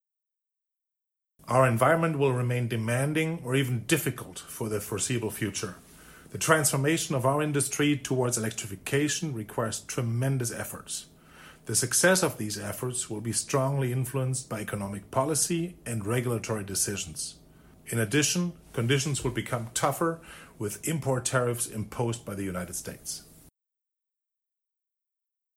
Recording of the annual press conference